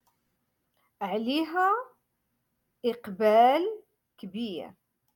Moroccan Dialect-Rotation five-Lesson Sixty